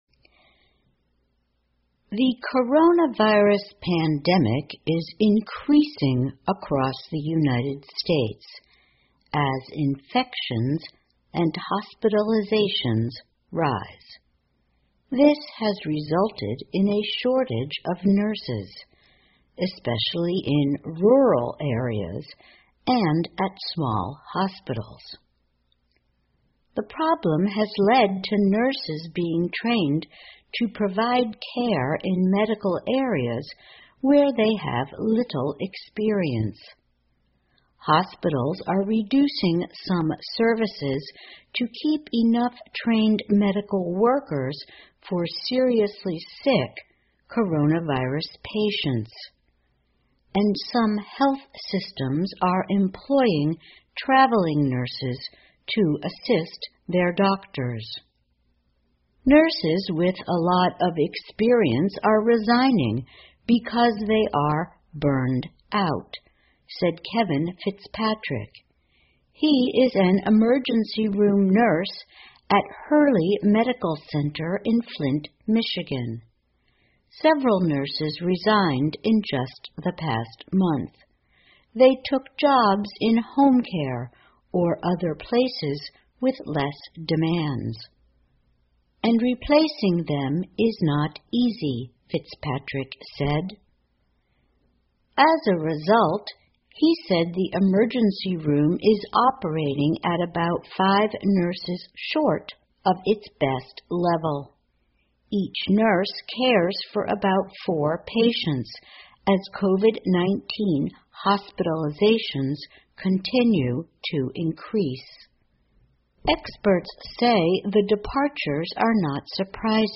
VOA慢速英语--美国冠状病毒病例增加医院争抢护士 听力文件下载—在线英语听力室